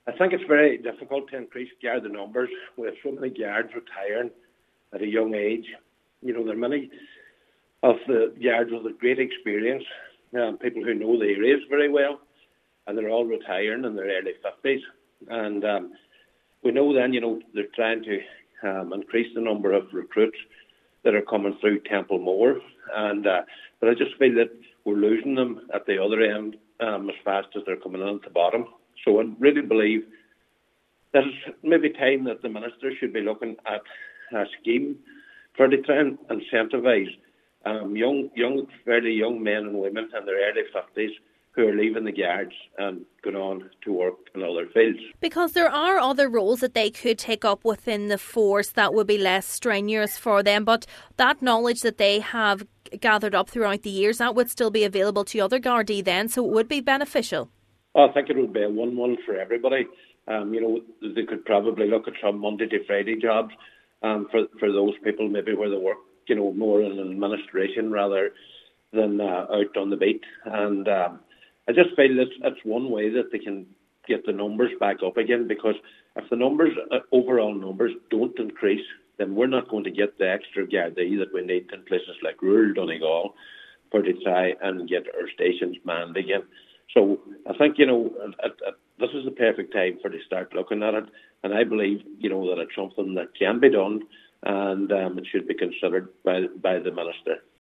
Councillor McBride believes measures need to be put in place to retain as many Gardai as possible: